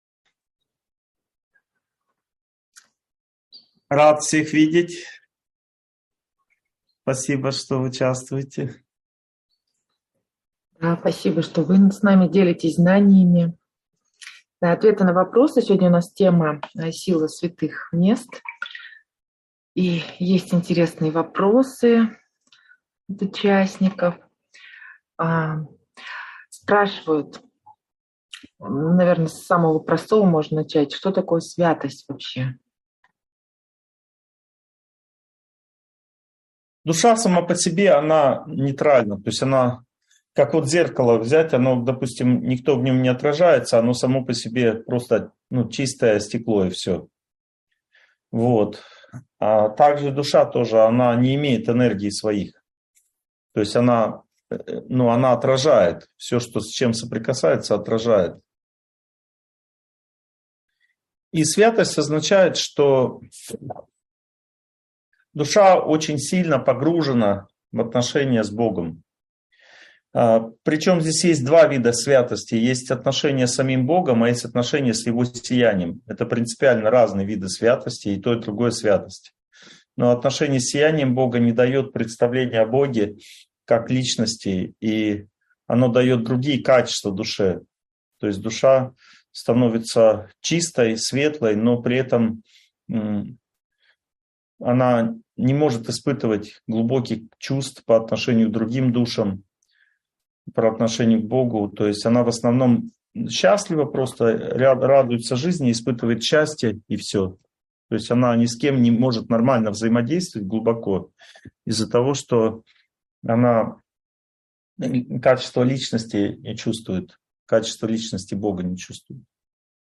Сила святых мест (онлайн-семинар, 2023)